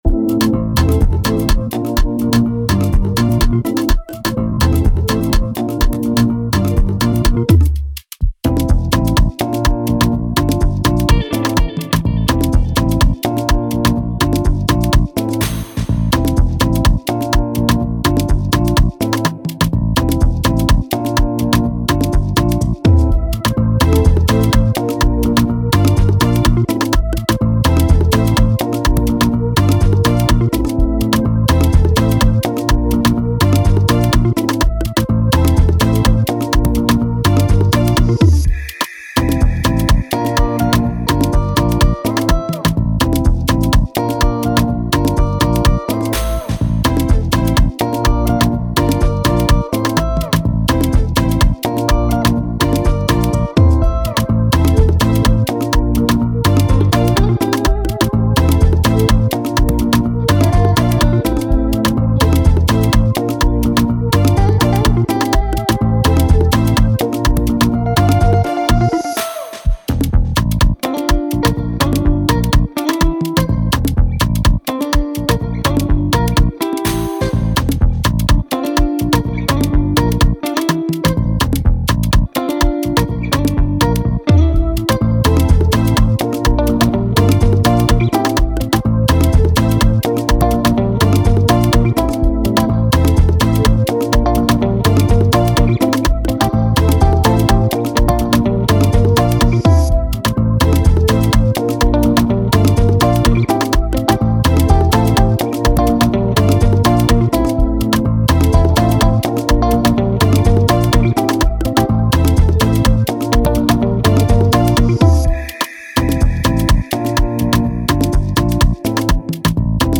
official instrumental
Dancehall/Afrobeats Instrumentals